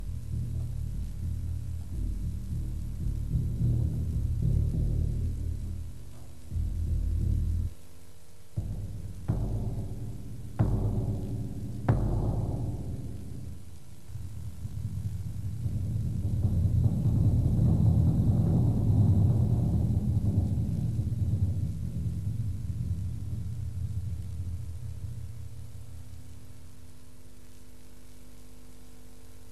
Percusión
BOMBO
Bombo.mp3